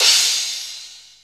CYM CRA13.wav